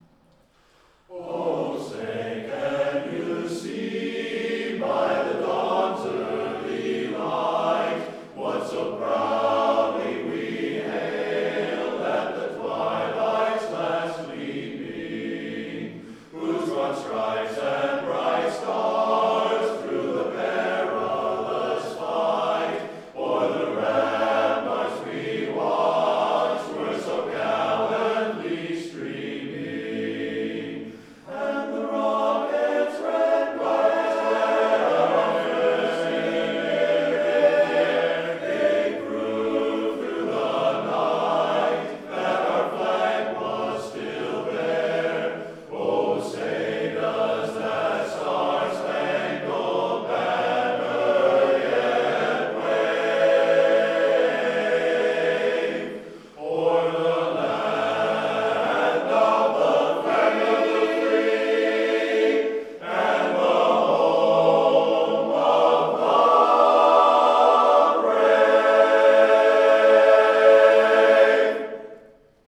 Up-tempo
Barbershop
A Major
Full Mix 2